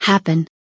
happen kelimesinin anlamı, resimli anlatımı ve sesli okunuşu